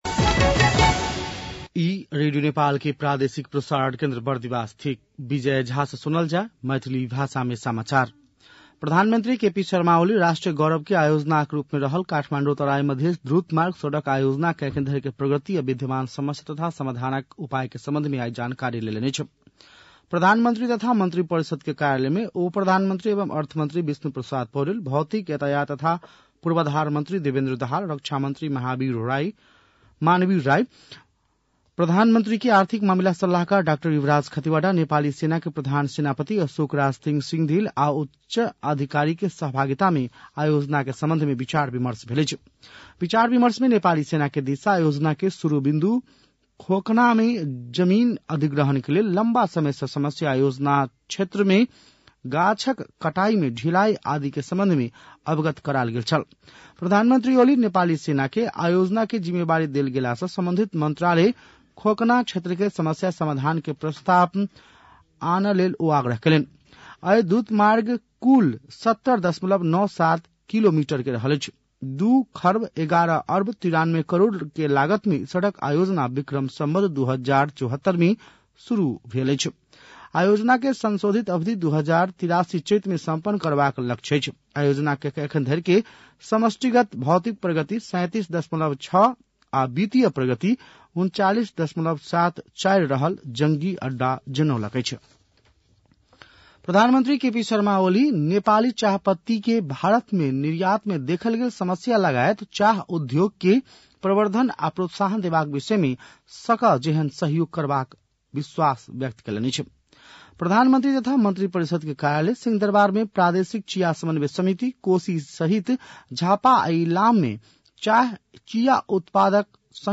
मैथिली भाषामा समाचार : २४ माघ , २०८१
Maithali-News-10-23.mp3